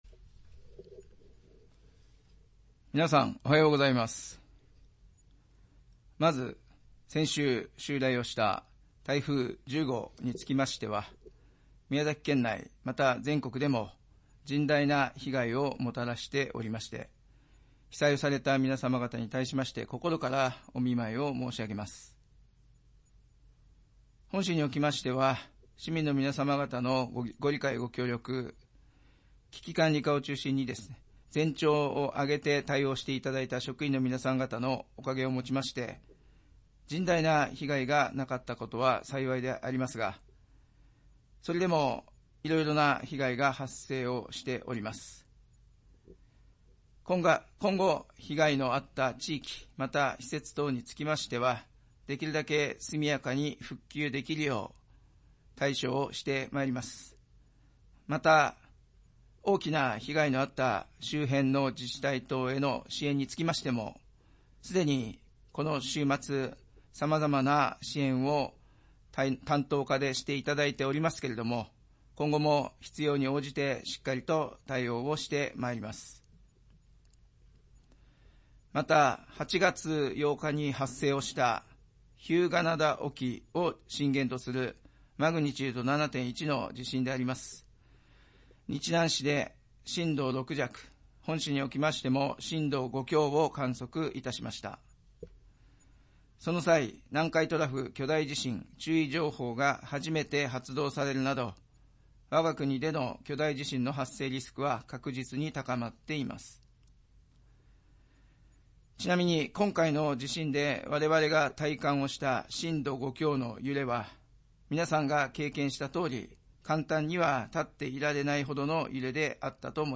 市長が毎月初めに行う職員向けの庁内メッセージを掲載します。